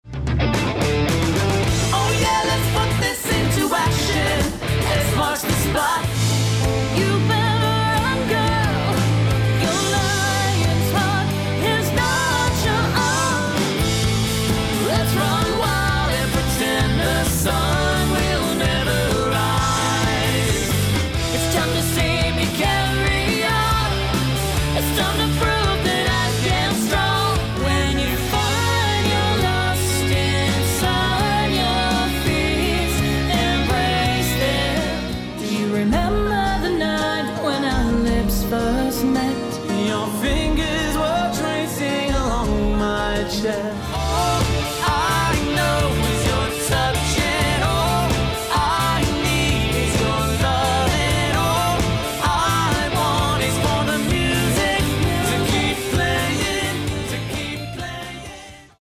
An exciting and eclectic mix of indie rock and pop